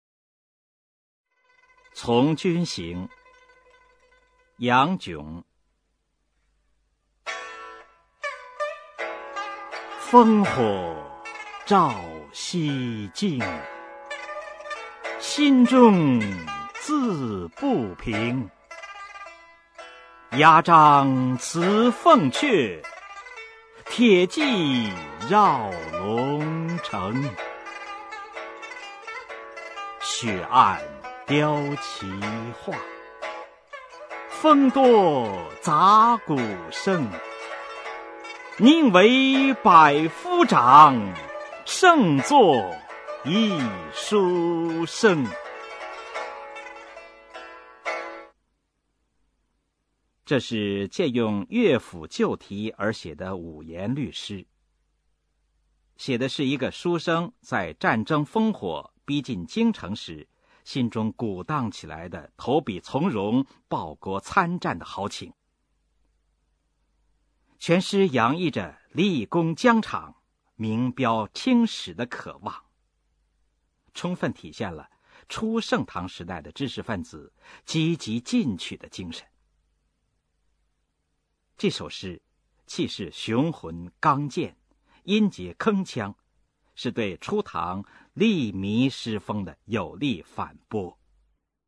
[隋唐诗词诵读]杨炯-从军行 配乐诗朗诵